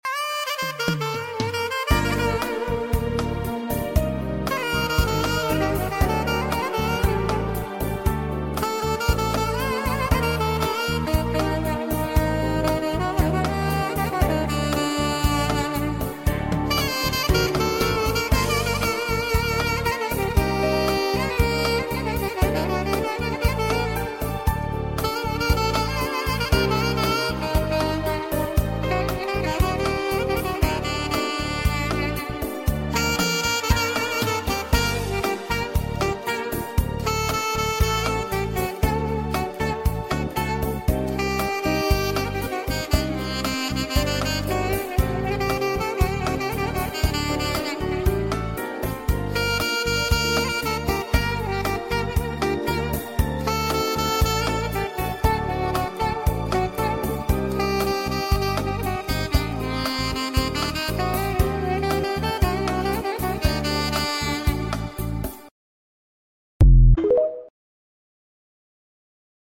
Австрия, Дорога в Альпийские горы. sound effects free download